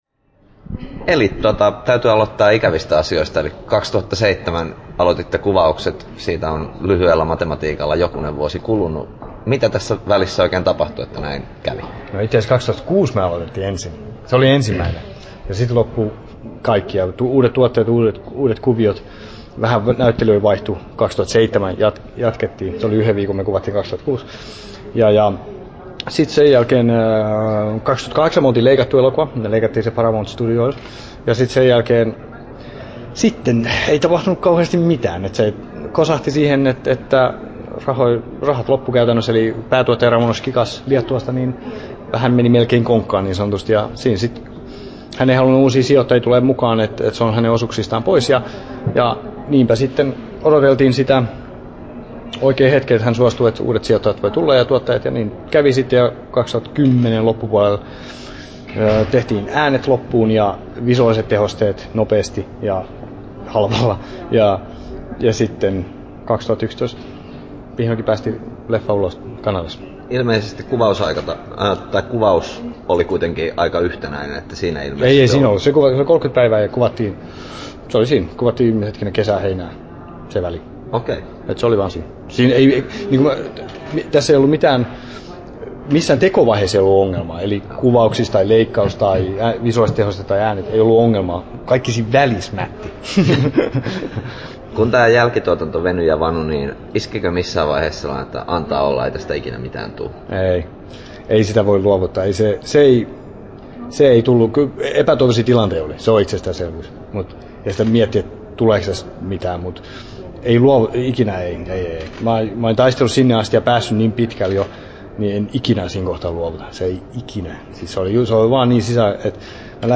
11'13" Tallennettu: 24.4.2012, Turku Toimittaja